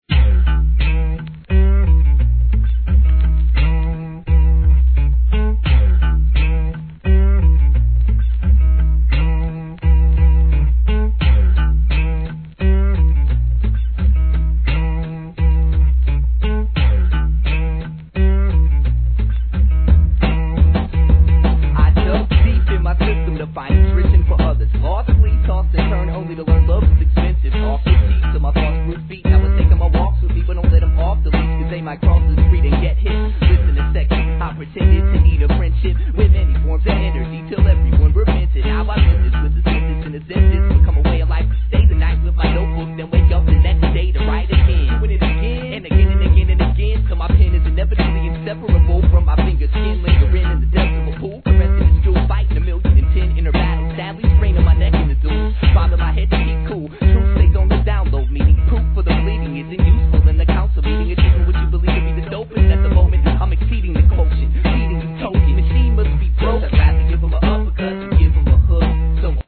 1. HIP HOP/R&B
シンプルなドラムにギターのみで創り上げる怒渋プロダクション!